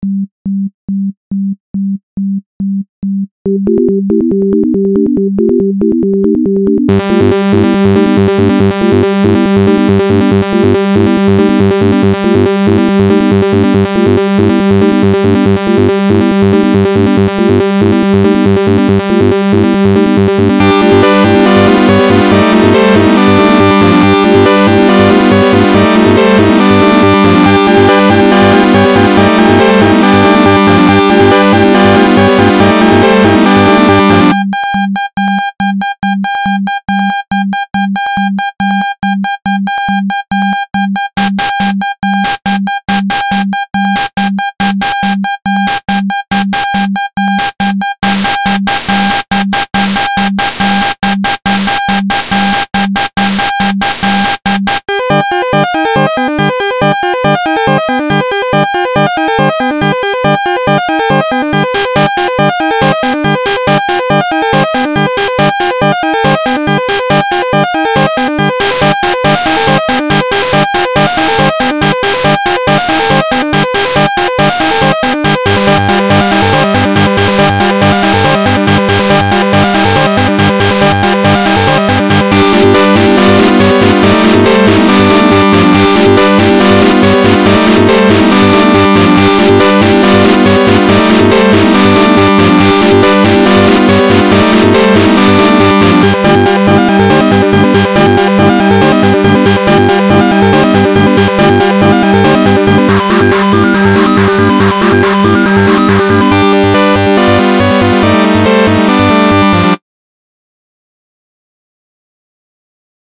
More exciting NES goodness.